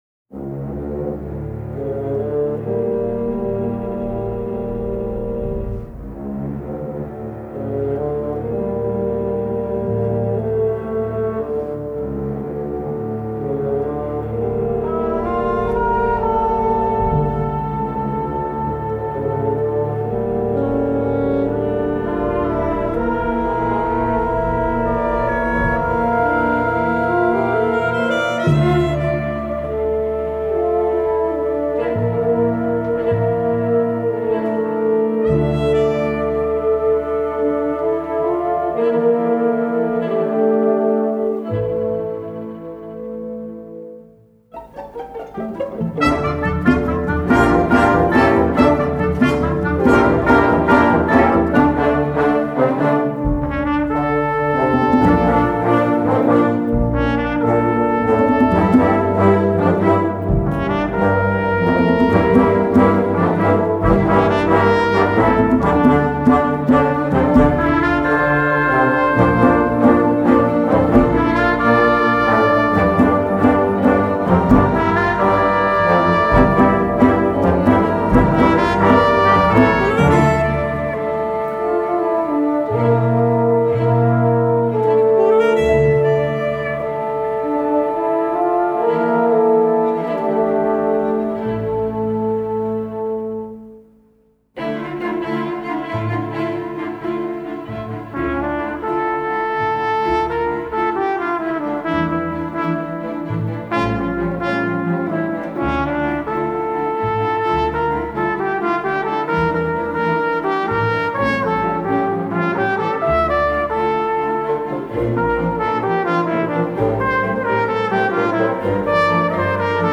Concerto for trumpet and orchestra.